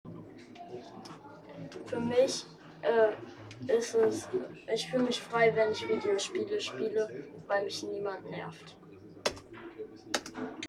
Standort der Erzählbox:
MS Wissenschaft @ Diverse Häfen